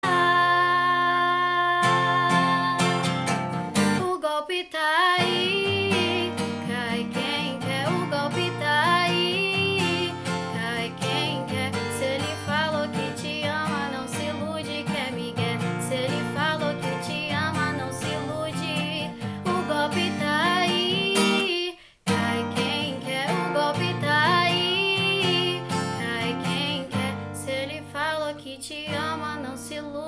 Categoria Eletronicas